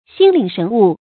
心領神悟 注音： ㄒㄧㄣ ㄌㄧㄥˇ ㄕㄣˊ ㄨˋ 讀音讀法： 意思解釋： 見「心領神會」。